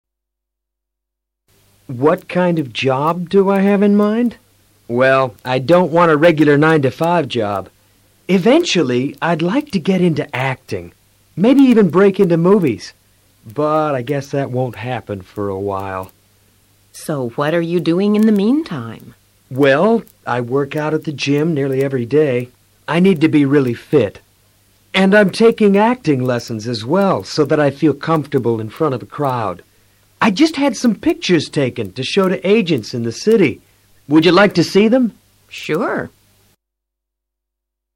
A continuación escucharás a tres entrevistadores.